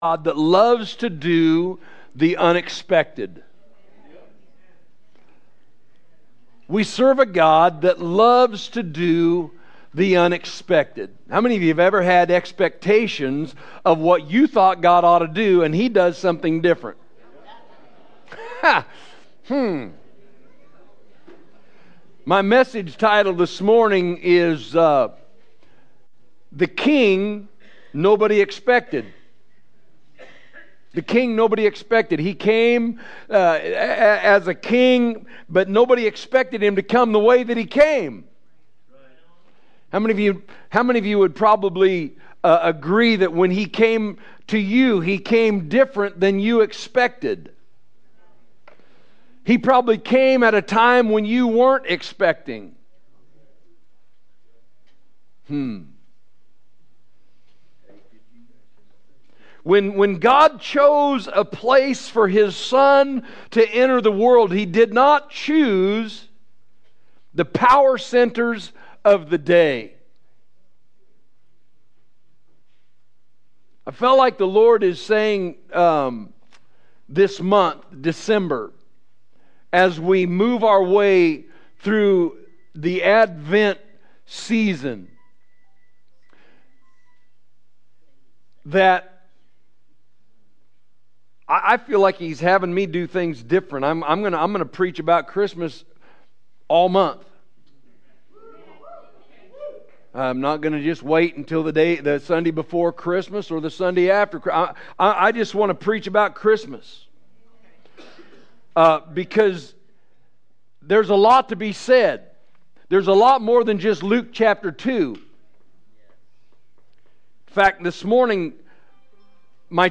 Sunday Morning Service December 7, 2025 – The King Nobody Expected
Recent Sermons